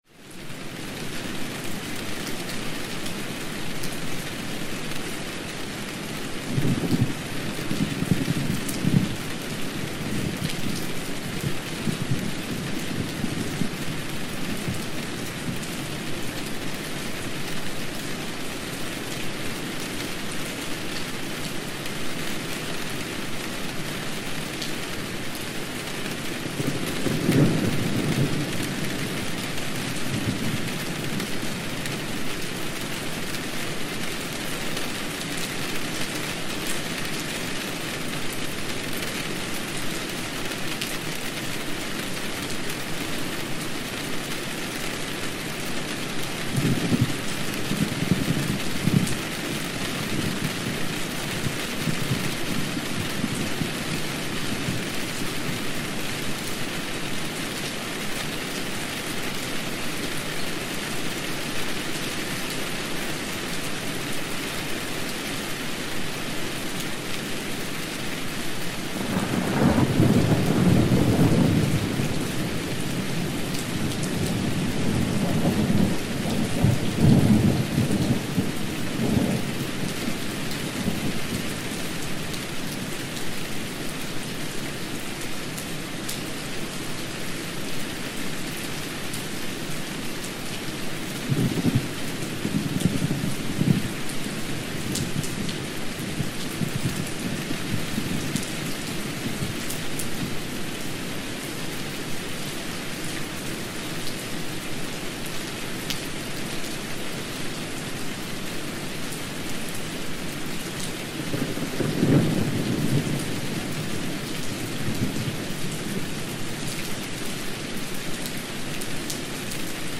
Natural Rainstorm Ambience – Rain and Thunder Sleep
Every episode of Rain Sounds is carefully crafted to deliver high-quality ambient rain recordings that promote deep sleep, reduce anxiety, and enhance mindfulness.